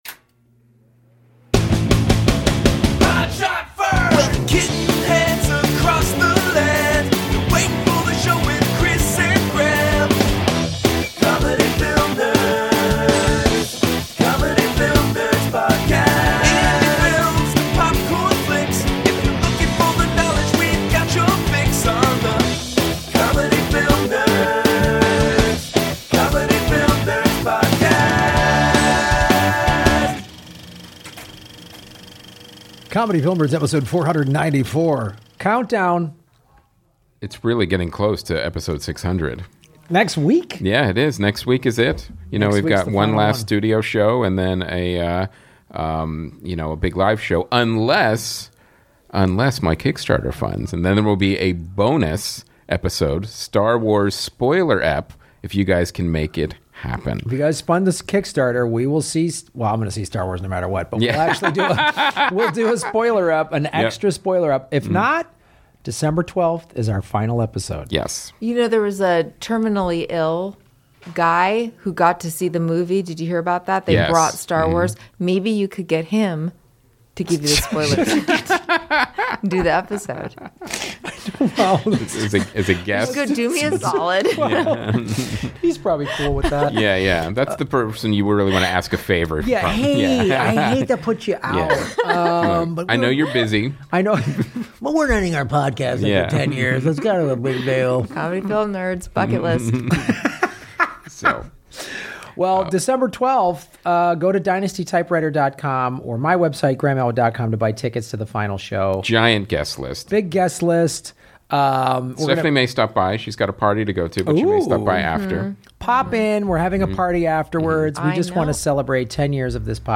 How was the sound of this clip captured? The second to last studio episode.